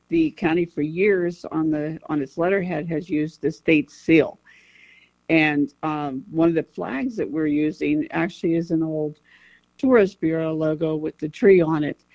County Commissioner Bonni Dunlap said that for years, the county has used other images on letterheads and flags.